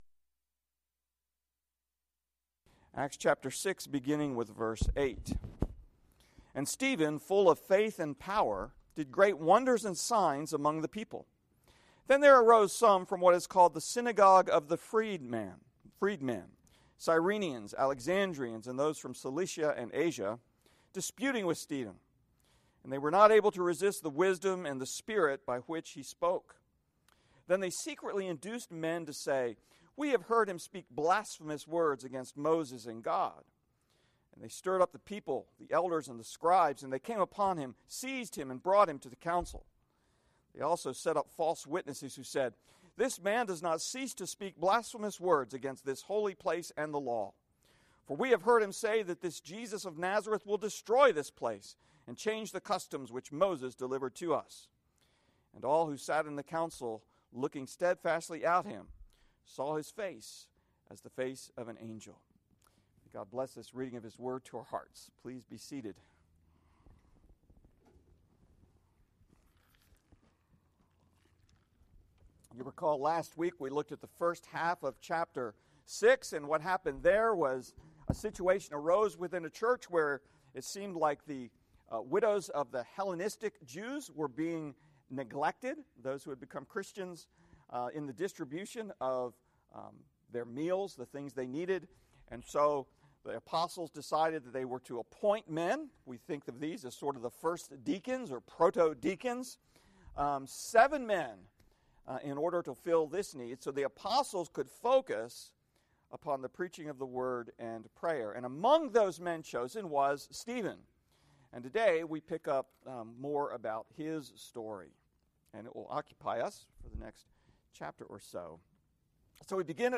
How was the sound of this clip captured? Evening Service